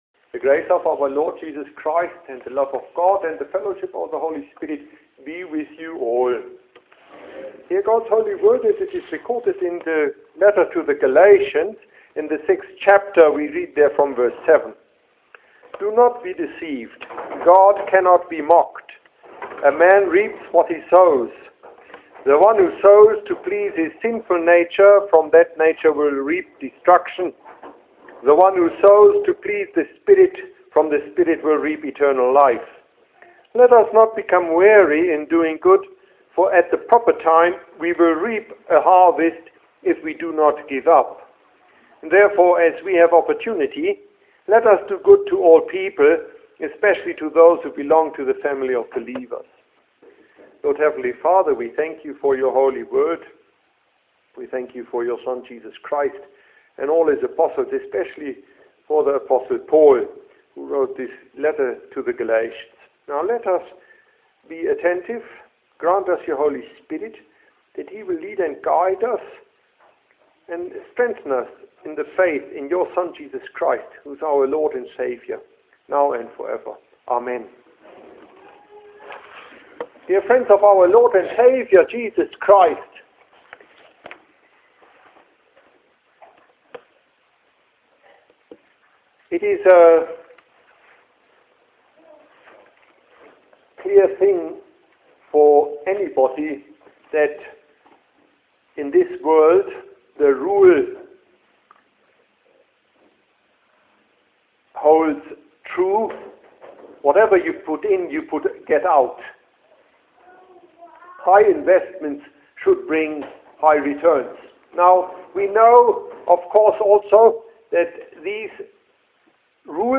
Sermonette on Gal.6:7-10